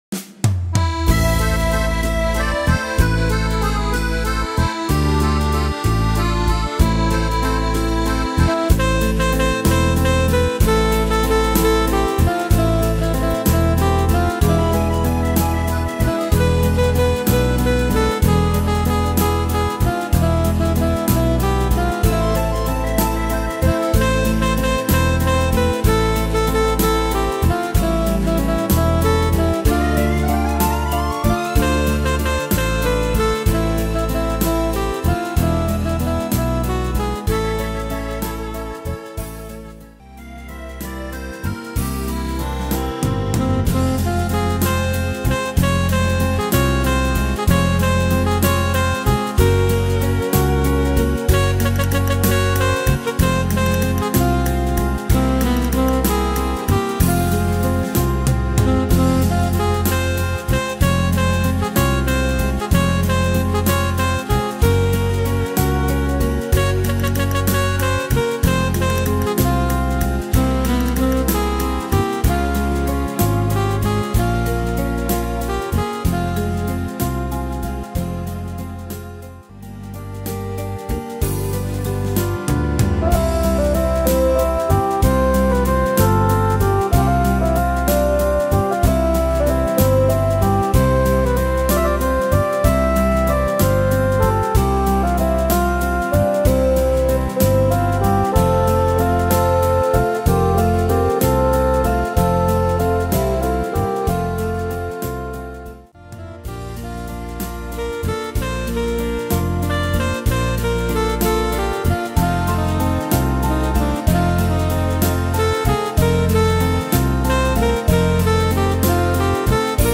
Tempo: 63 / Tonart: A-moll
Inst.